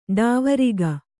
♪ ḍāvariga